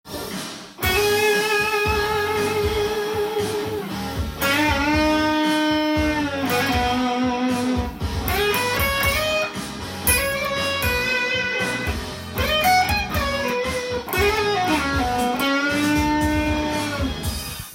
音源に合わせて譜面通り弾いてみました
イントロのエレキギターはまさのその雰囲気が満載の
カッコいギターソロからスタートしています。
keyがA♭ｍになっているので
A♭ｍペンタトニックスケールでブルージーに弾いています。
チョーキングやスライド、ハンマリングやプリング